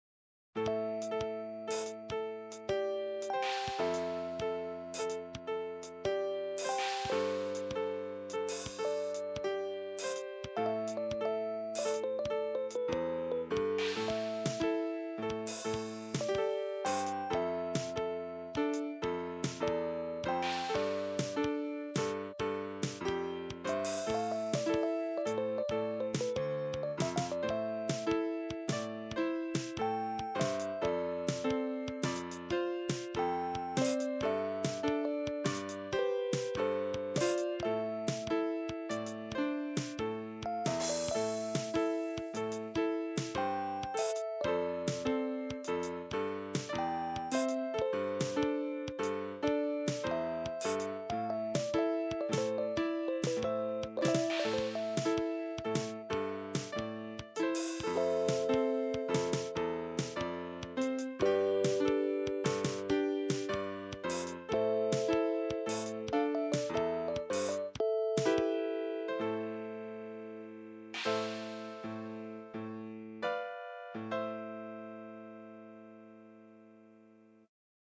Short Fast Paced Tune Action Style with Drums